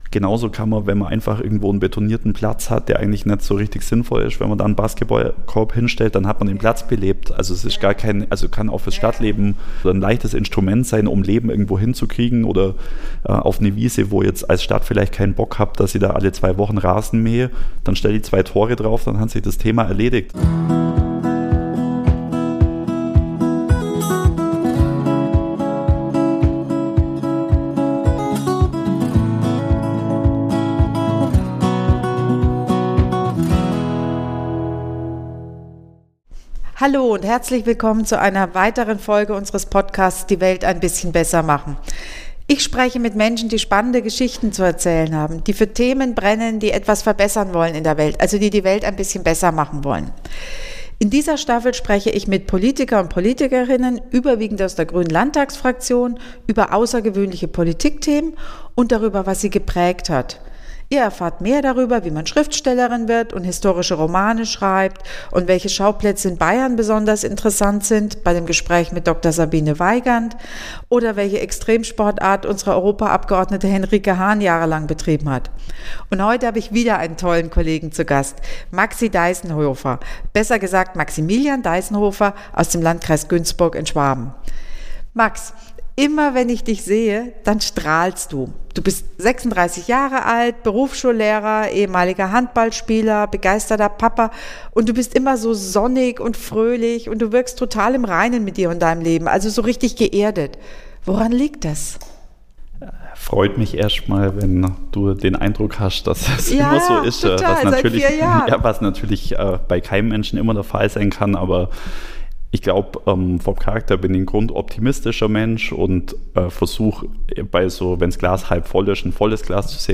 Die Abgeordneten kommen aus allen Regionen des Freistaats - das hört man nicht zuletzt an den verschiedenen Dialekten.